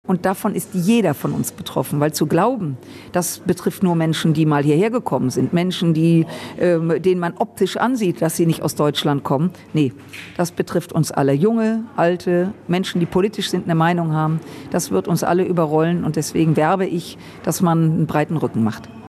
Strack-Zimmermann war als Rednerin beim Neujahrsempfang der Hagener FDP zu Gast.